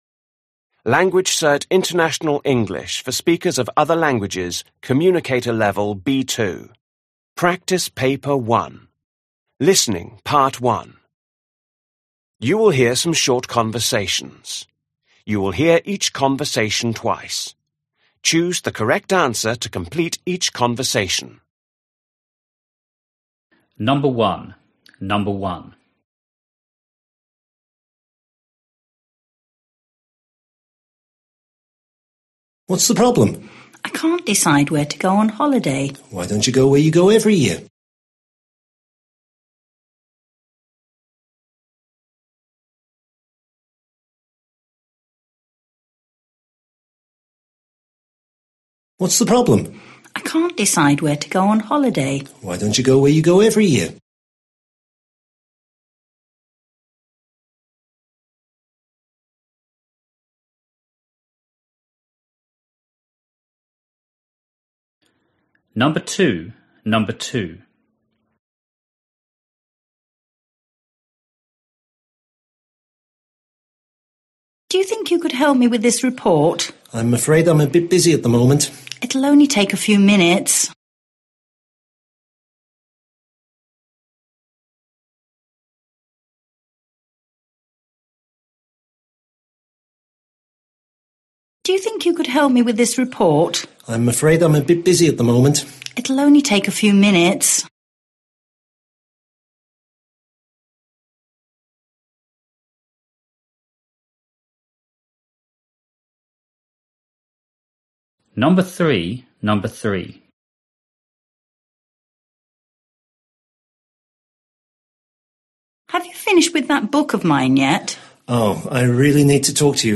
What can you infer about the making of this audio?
You will hear some short conversations. You will hear each conversation twice.